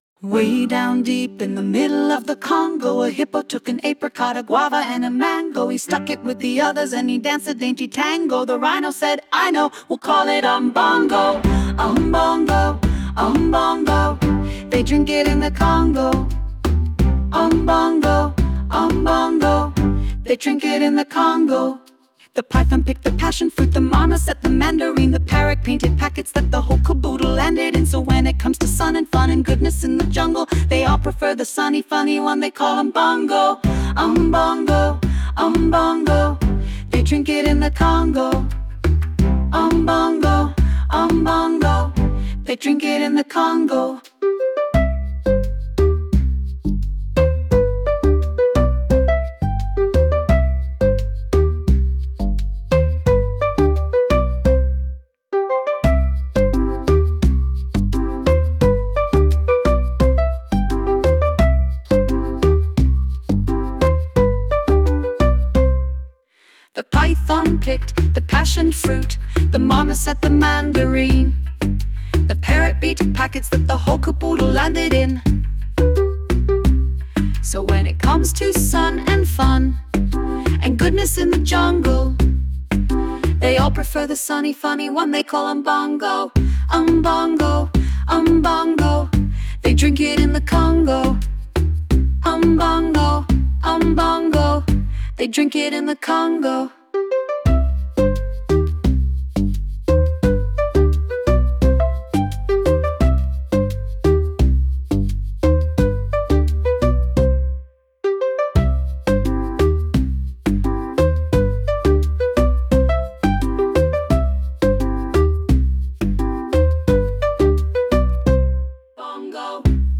8. 1990s Charity Single